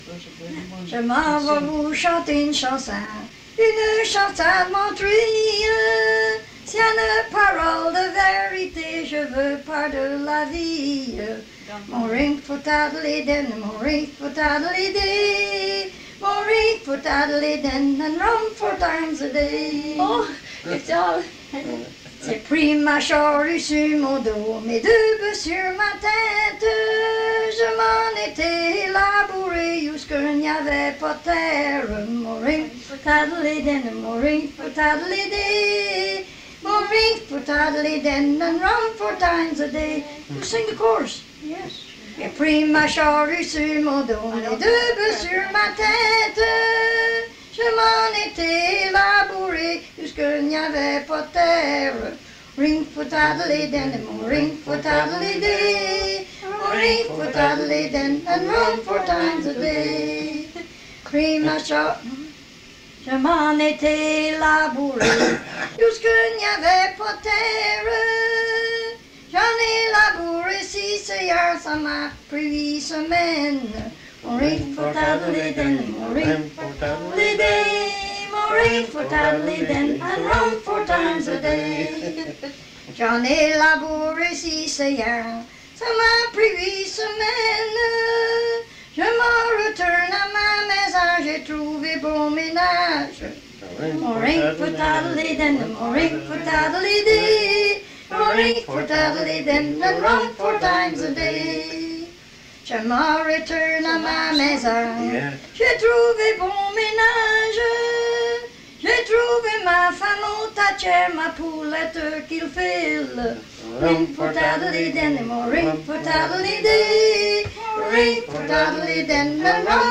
Chanson
Emplacement Upper Ferry